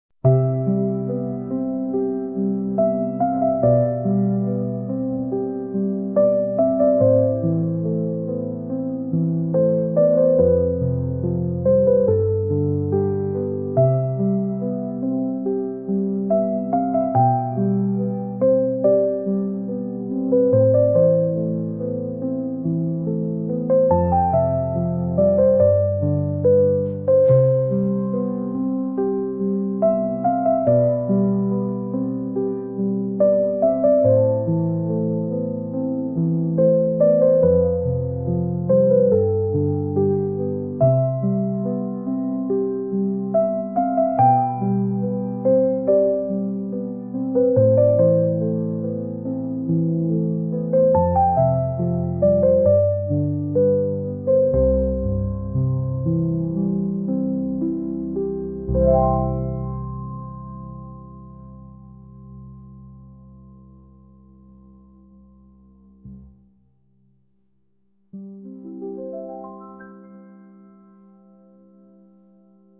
Guitar Music
beautiful and reverent guitar piece